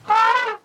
A short sound effect of an elephant's trumpet, intended for use with my Mastodon application's notifications.